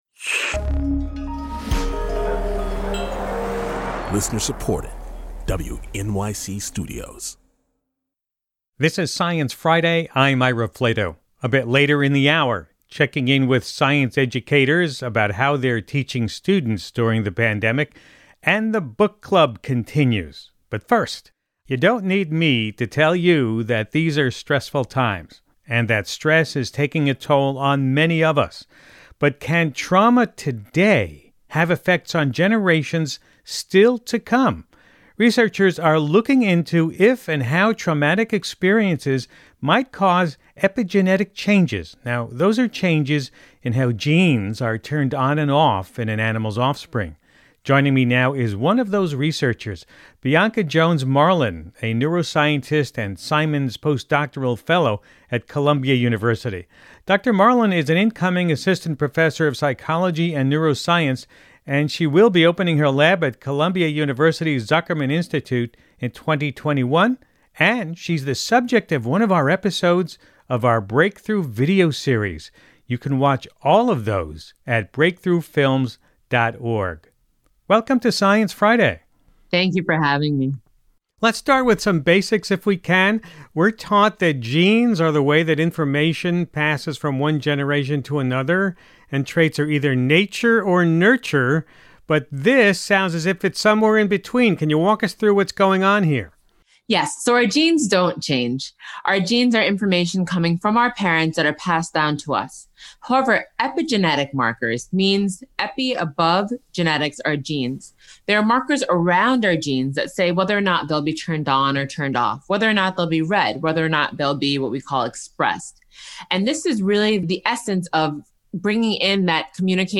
roundtable discussion